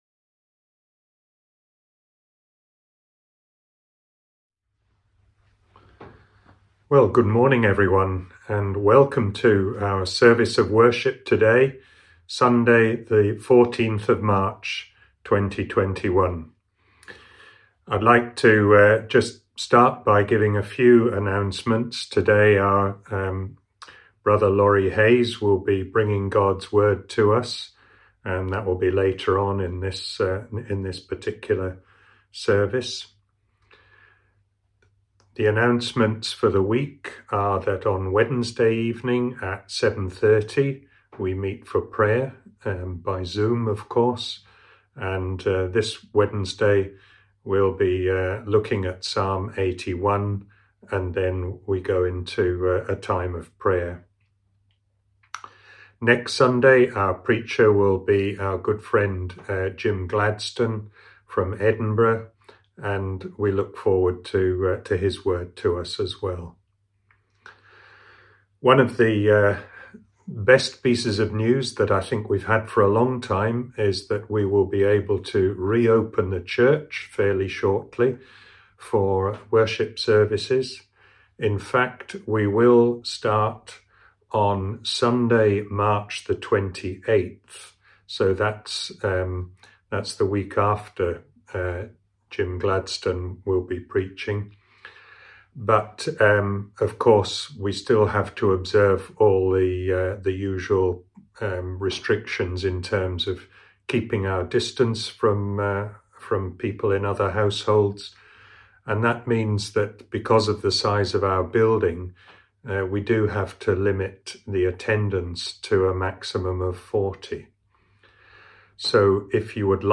Morning Service 14th March 2021